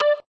tone_2.ogg